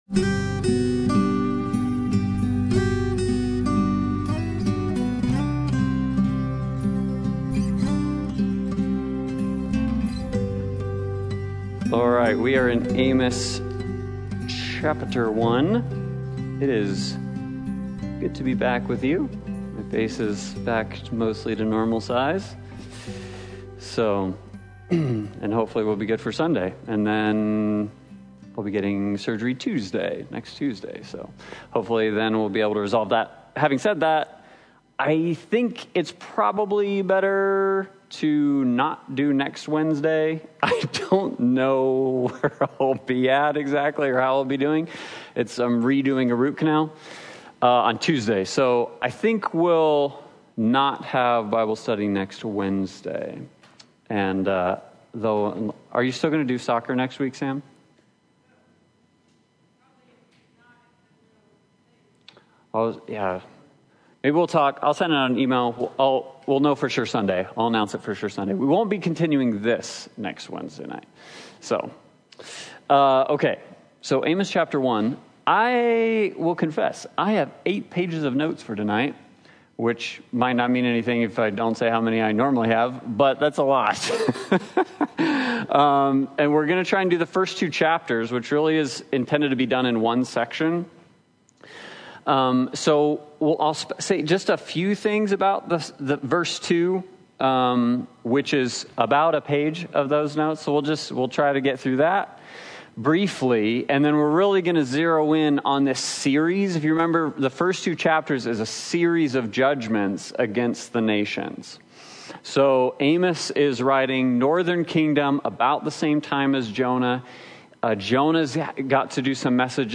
The Book of the Twelve Service Type: Sunday Bible Study « The Breath of Life